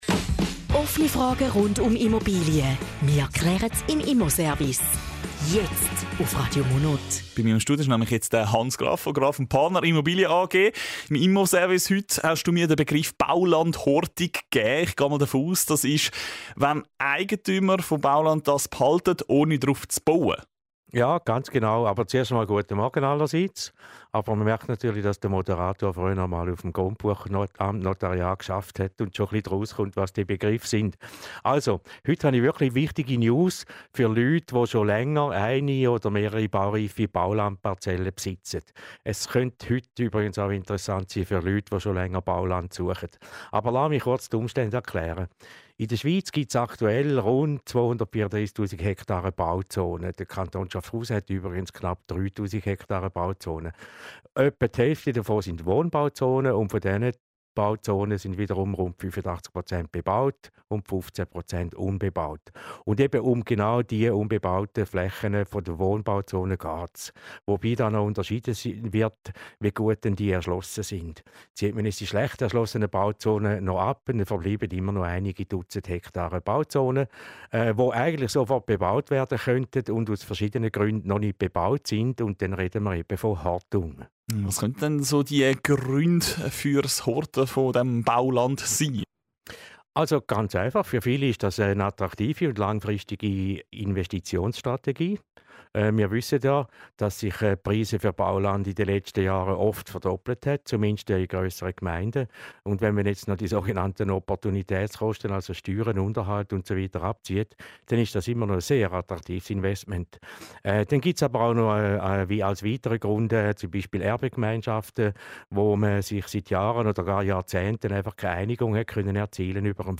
Zusammenfassung des Interviews zum Thema Baulandhortung und wichtige Neuerungen: